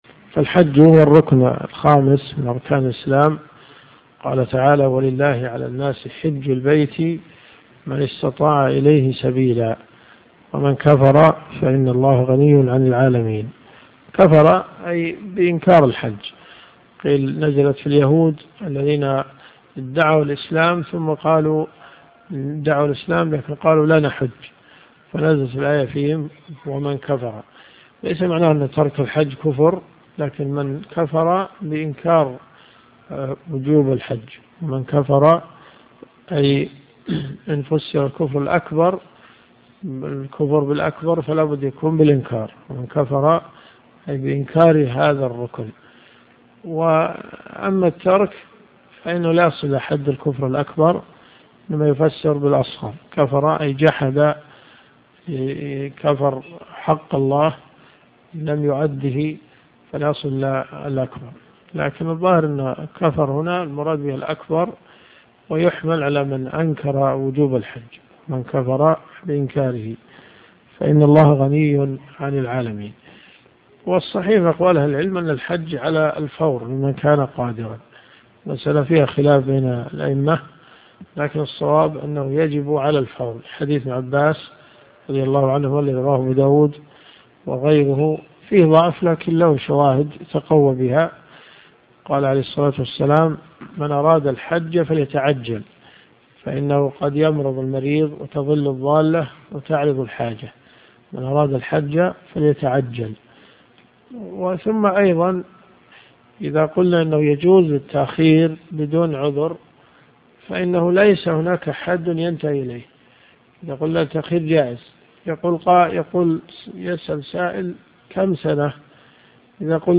قسم المحاضرات . محاضرة ما قبل الحج إلى العمرة .